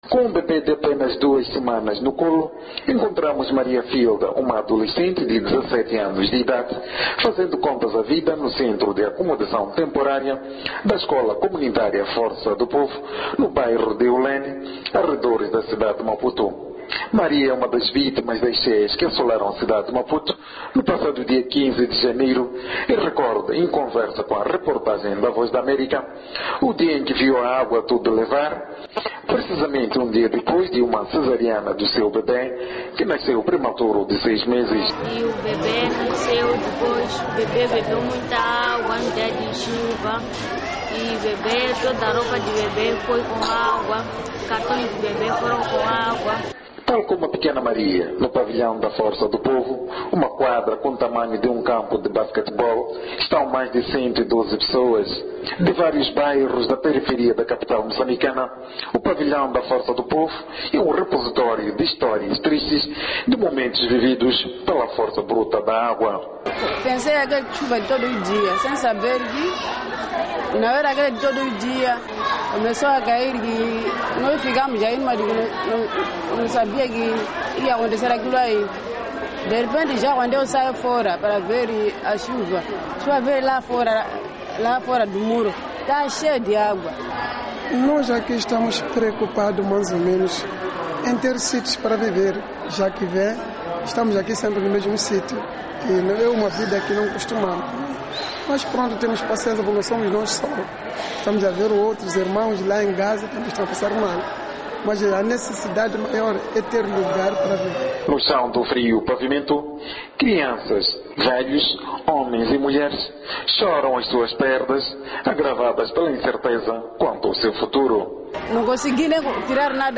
A reportagem da Voz da América ouviu as histórias do drama em que vivem milhares de moçambicanos.